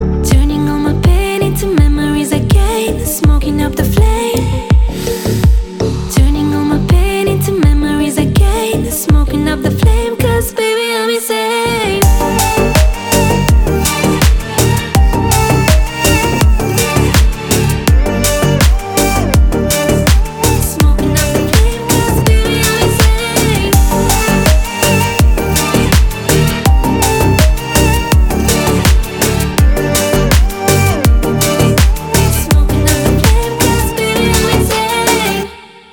ритмичные
deep house
Electronic
красивый женский голос
Стиль: deep house